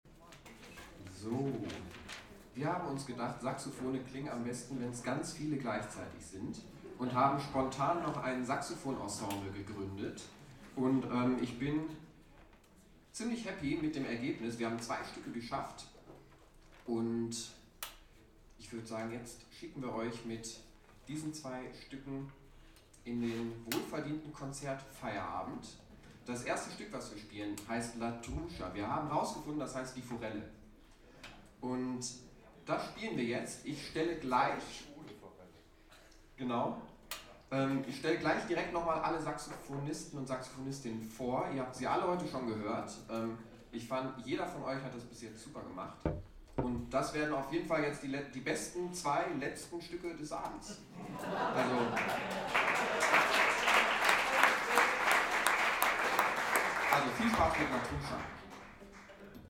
27 - Gruppe Saxophoner - Ansage.mp3